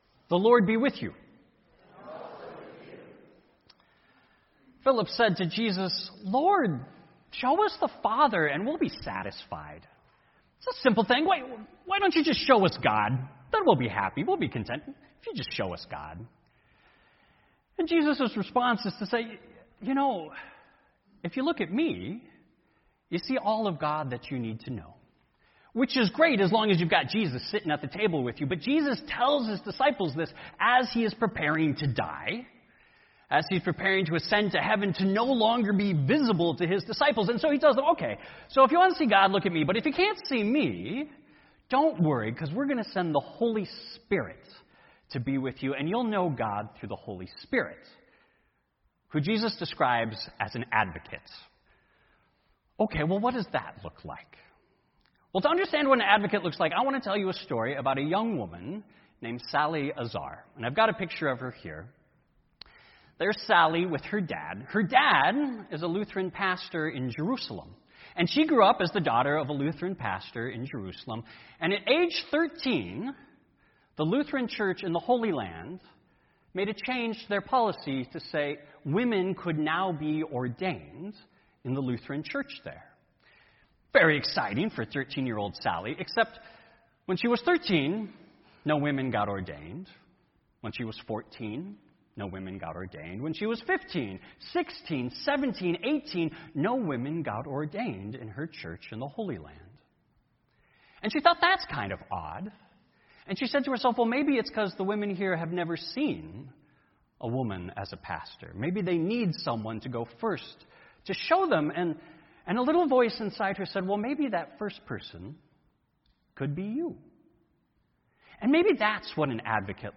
Sermon: When we can't see the Father and we can't see the Son, we can see the Holy Spirit, the Advocate who works in everyone.